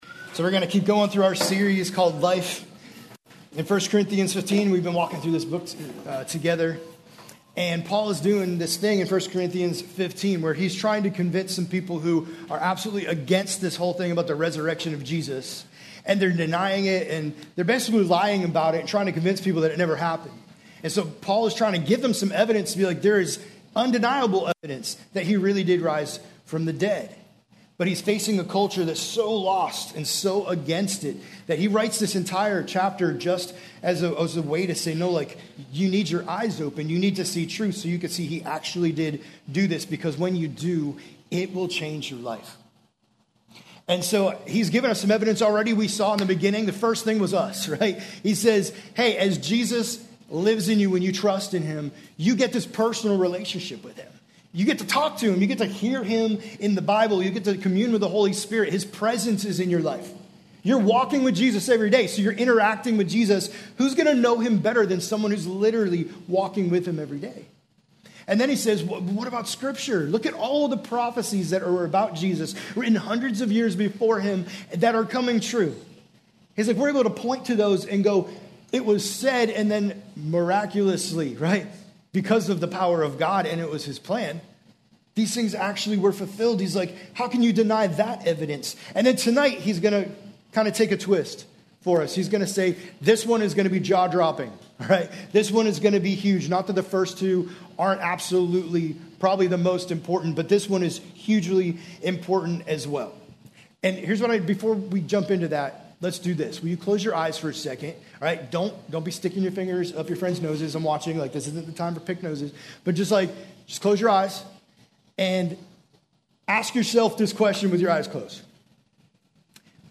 Junior High messages from the Firehouse Student Ministry